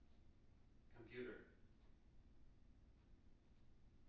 wake-word
tng-computer-308.wav